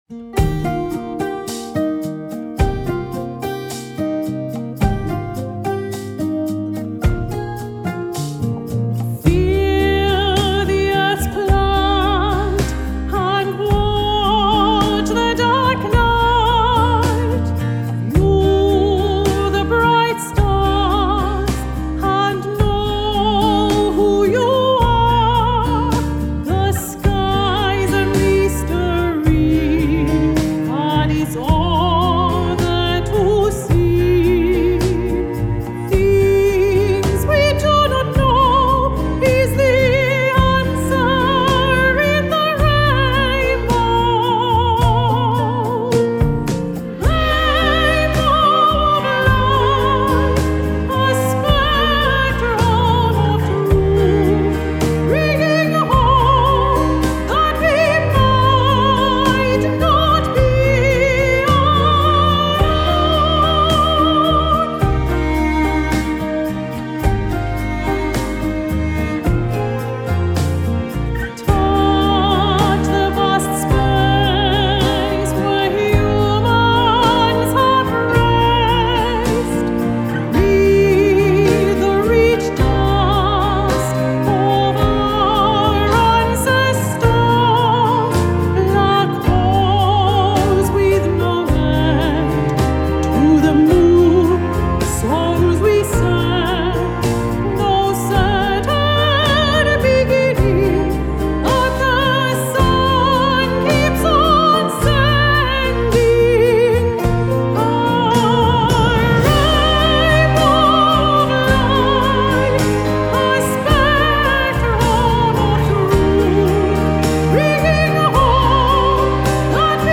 With over twenty minutes of classical/folk/ crossover tracks
crystal clear spinto soprano voice
modern acoustic sounds
Airtight Studios in Manchester